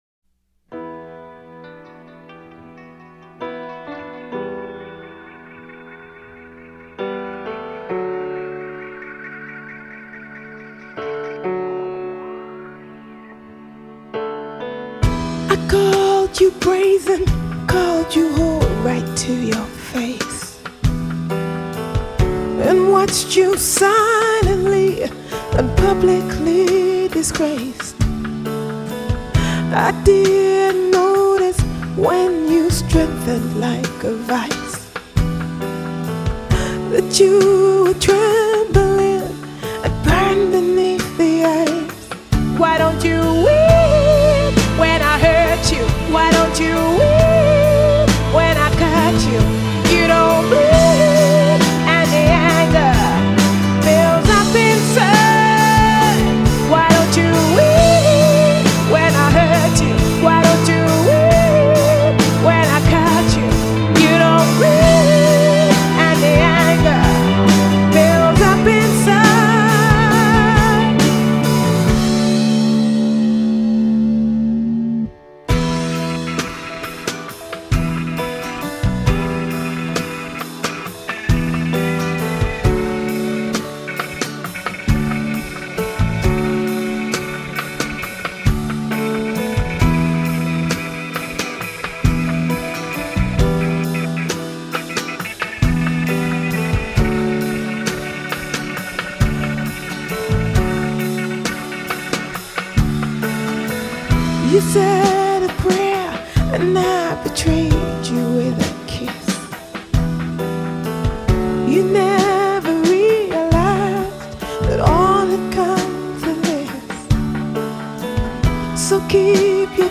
(Instrumental)
(A Capella)